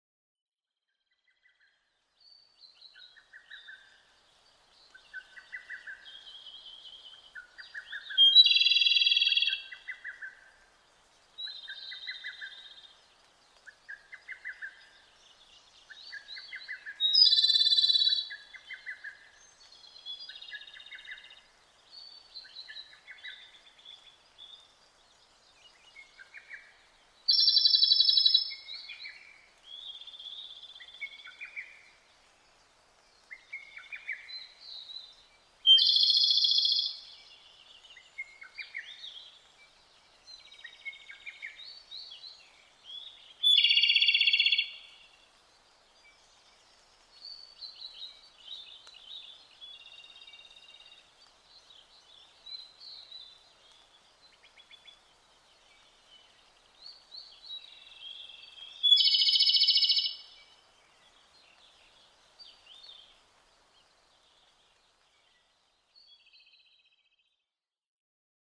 コマドリ　Erithacus akahigeツグミ科
日光市稲荷川上流　alt=1330m
Mic: built-in Mic.
夜が明けて間もない頃、コマドリの声があちらこちらから聞こえてきます。
他の自然音：ホトトギス、アカハラ、コルリ